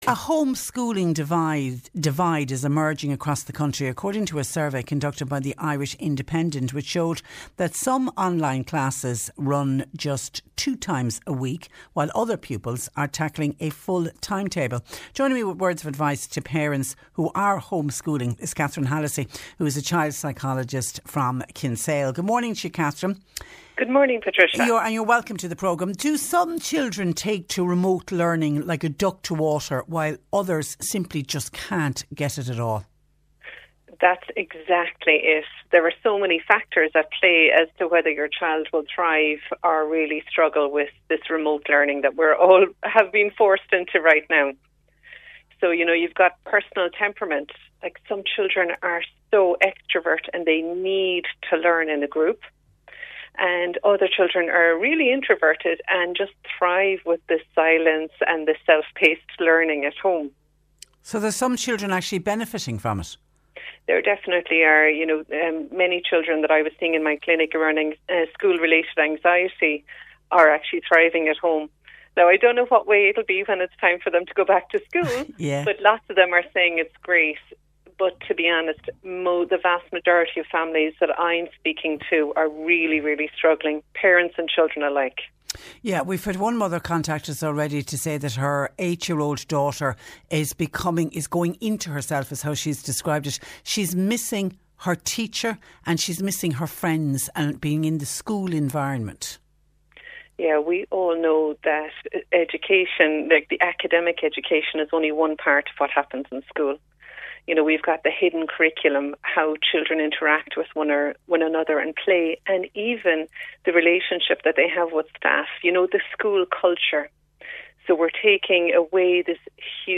Mentioned in the interview: ‘How to Homeschool without Losing your Mind’ online workshop for parents